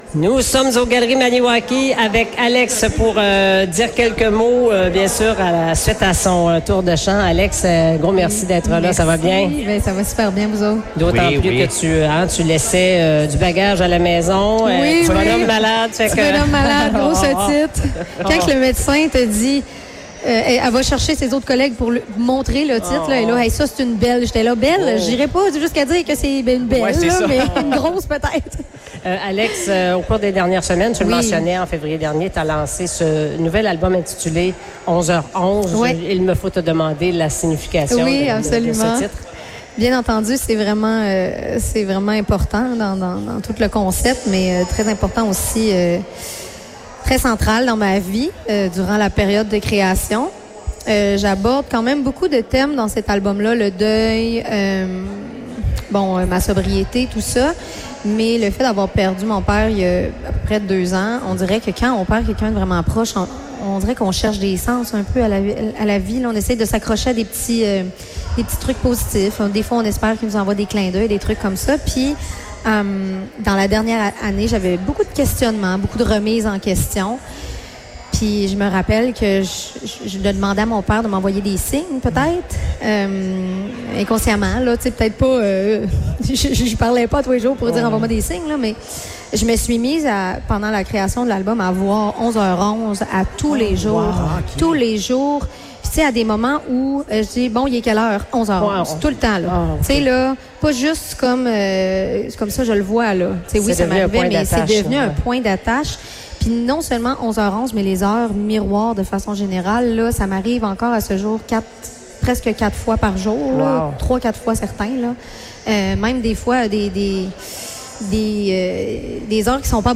Radiothon 2026 - Entrevue